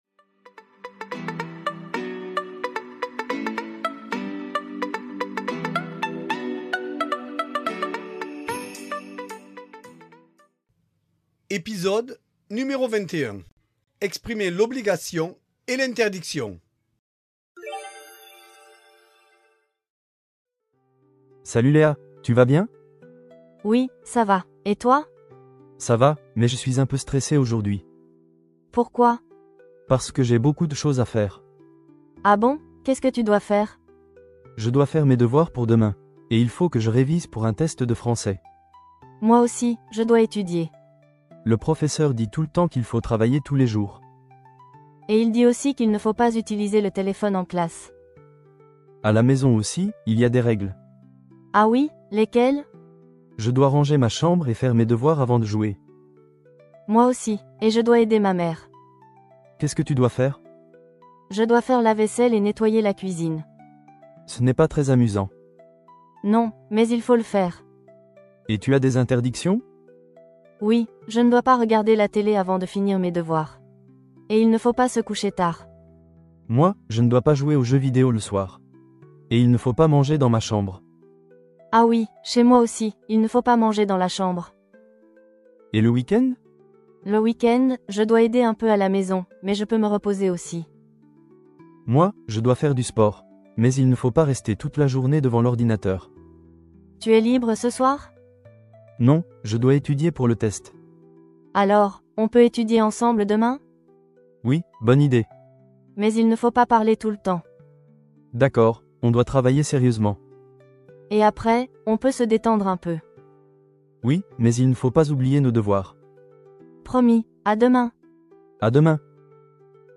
Voici un dialogue pour les élèves de niveau A1 sur une conversation entre deux amis pour apprendre a exprimer l’ obligation et l’ interdiction.